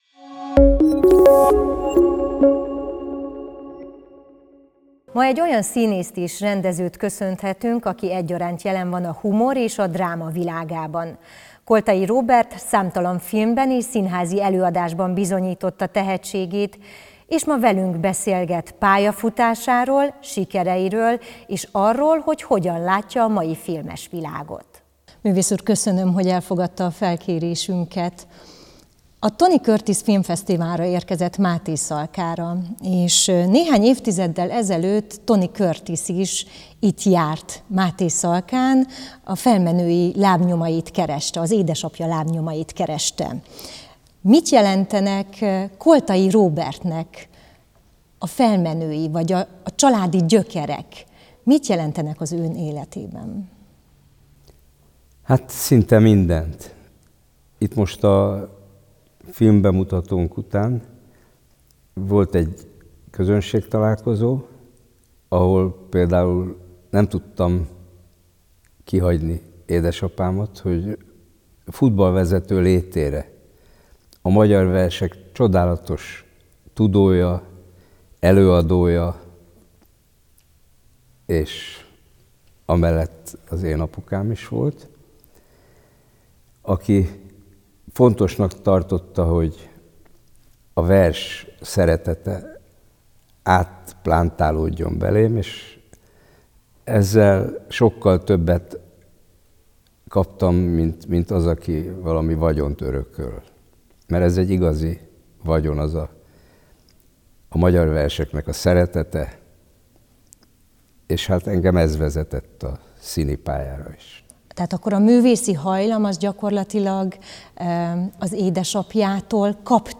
Beszélgetés Koltai Róbert színművésszel
Koltai Róbert szinművész, rendező is megtisztelte jelenlétével a filmfesztivált és a Médiacentrum Mátészalka felkérésére mesélt pályafutásáról, sikereiről és a magyar filmek világáról.